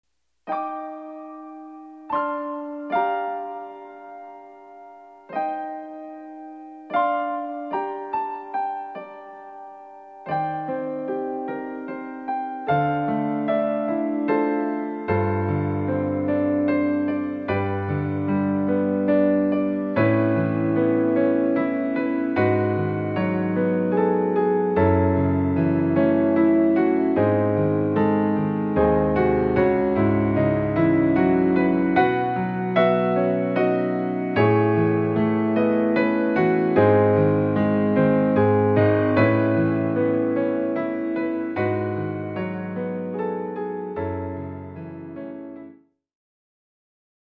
今のところ、ピアノのみの試し弾き、絵に例えれば白黒のスケッチのようなものです。